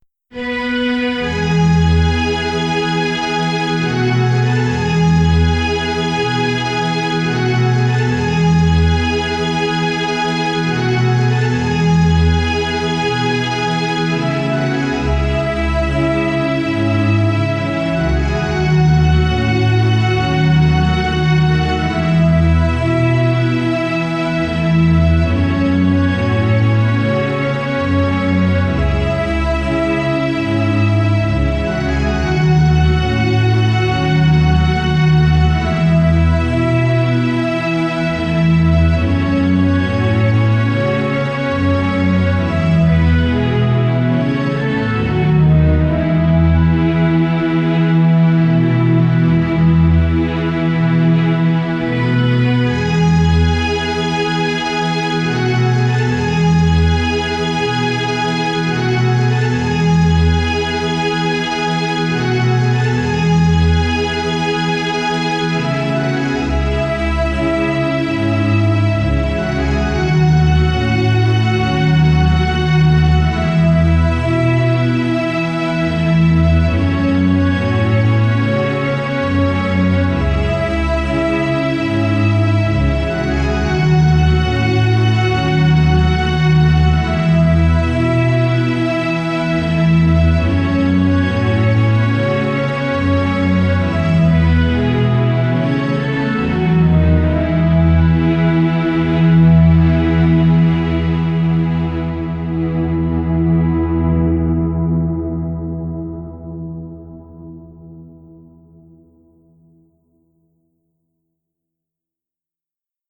E major
Symphonic music
6 instruments
cello
alto
violin
computer